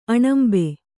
♪ aṇambe